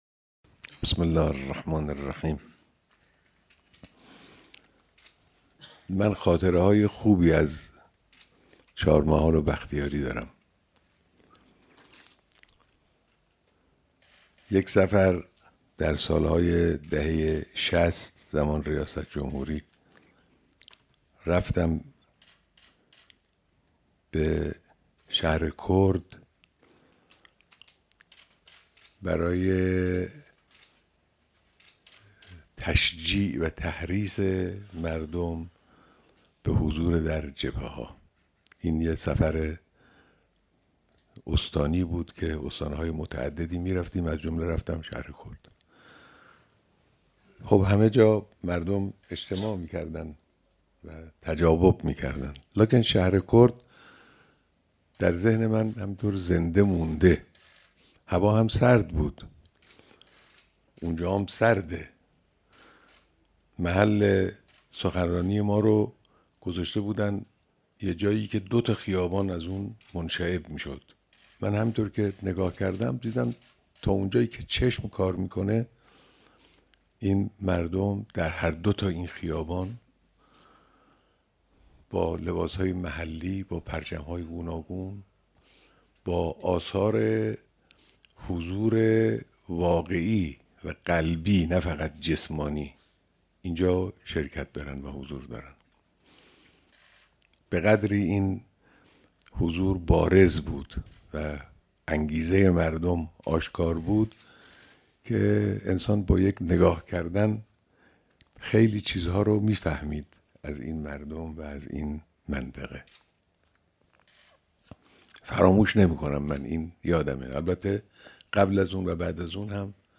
صوت/ بیانات رهبر انقلاب در دیدار اعضاى کنگره‌ شهدای چهارمحال و بختیارى‌